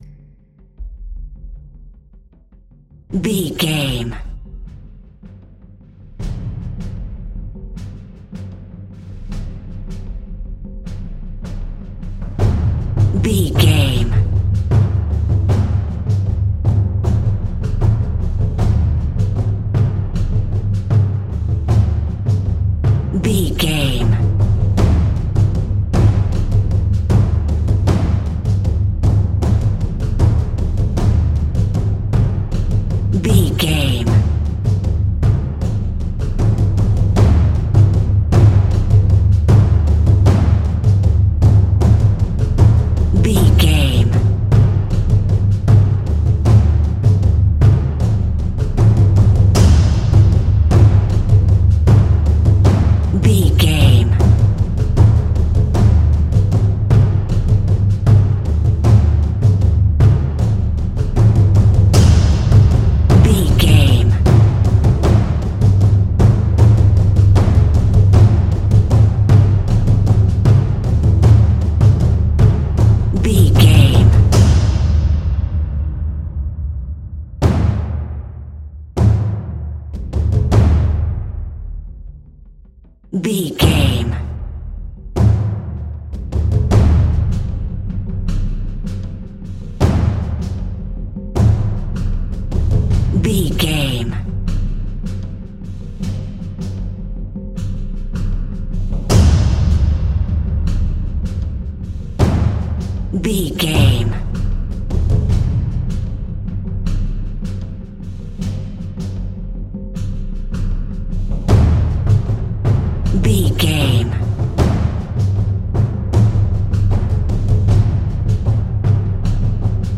Exotic and world music!
Atonal
SEAMLESS LOOPING?
World Music
ethnic music
strings
brass
percussion
cymbals
gongs
taiko drums
timpani